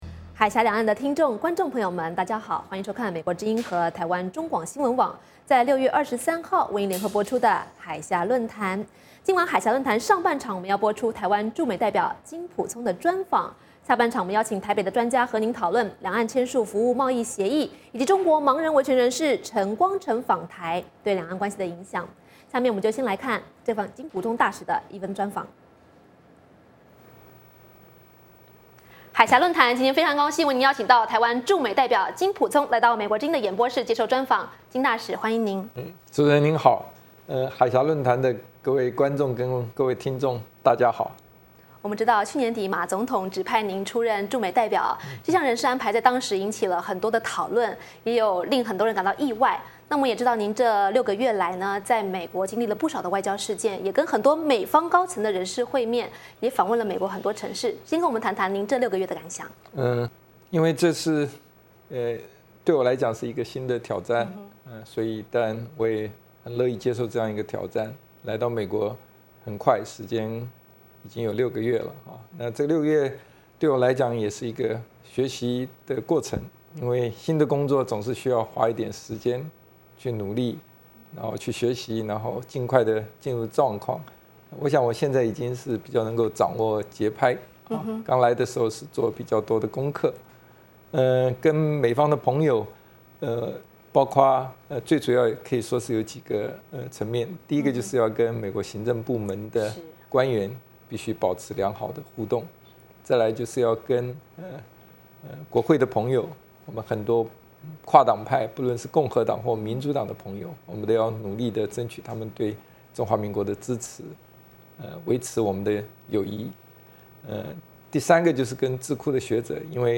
海峡论谈专访台湾驻美代表金溥聪